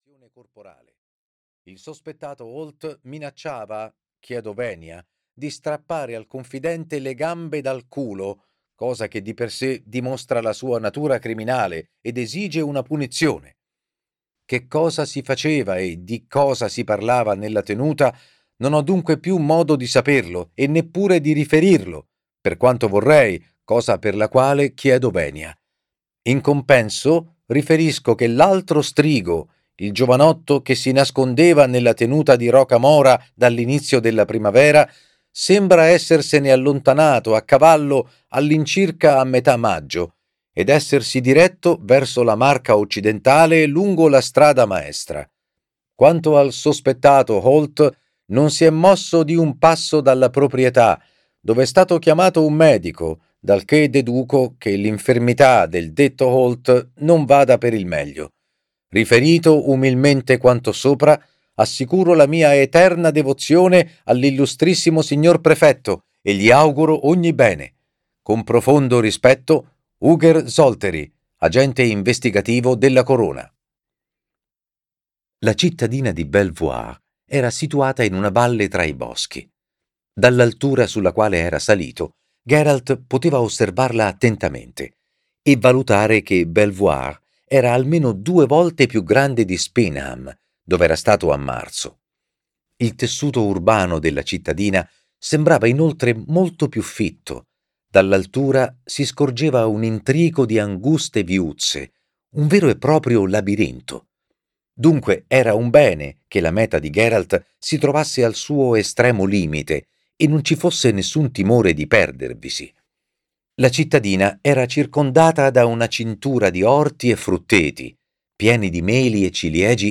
"Il crocevia dei corvi" di Andrzej Sapkowski - Audiolibro digitale - AUDIOLIBRI LIQUIDI - Il Libraio